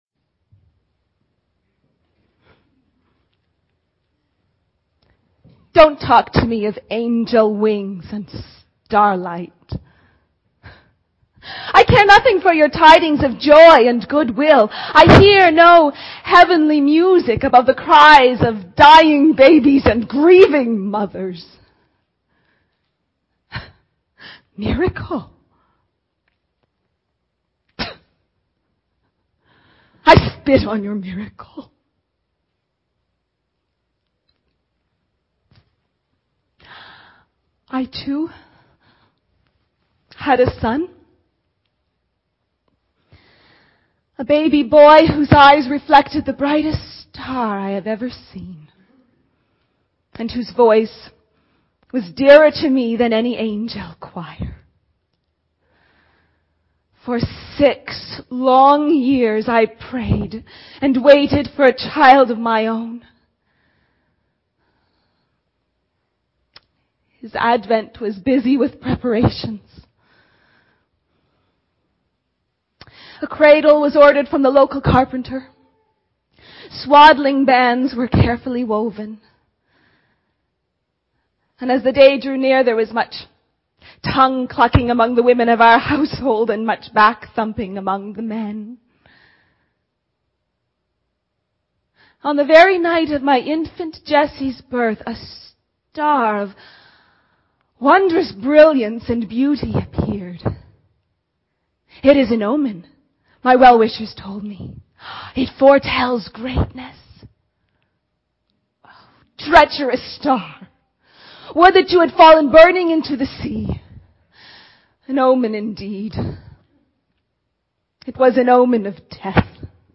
We say "Merry Christmas" because we expect it to be a happy experience ---- but in real life this isn't always the case --- and that's always been the case. (This sermon begins with a dramatic monologue entitled "Rachel Weeping,"